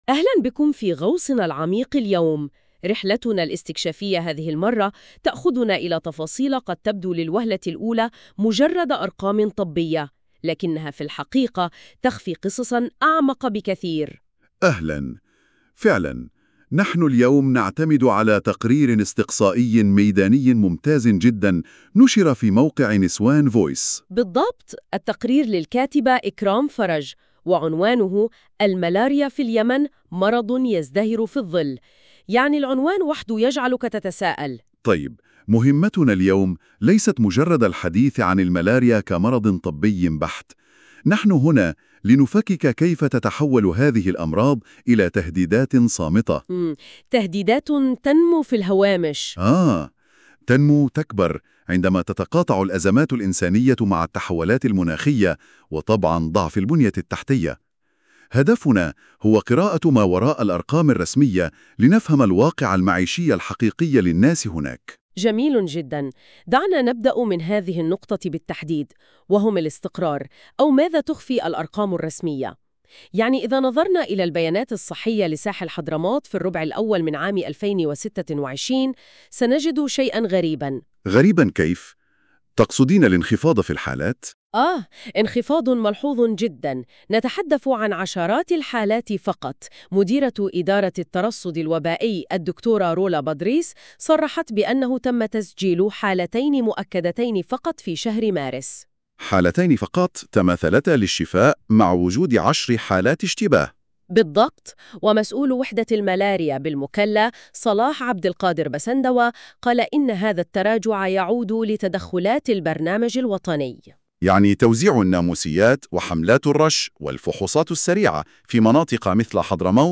ملخص صوتي للمادة المكتوبة مولد بالذكاء الاصطناعي بواسطة Notebook LM